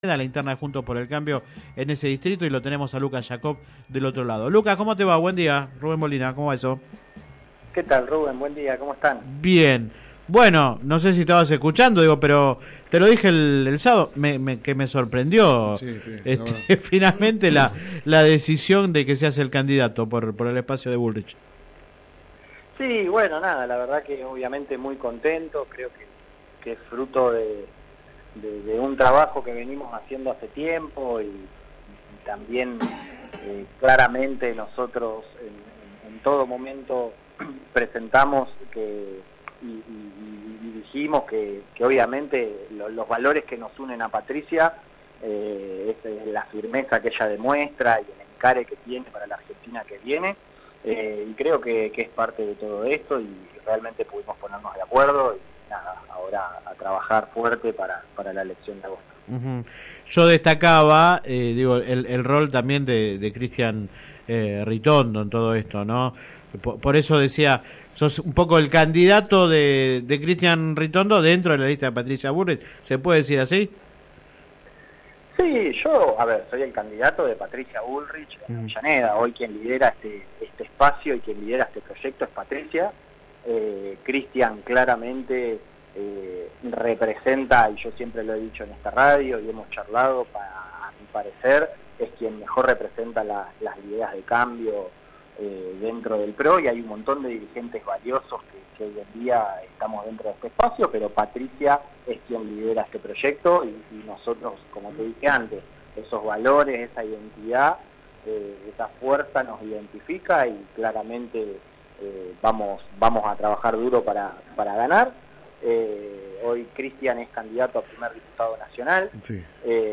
Click acá entrevista radial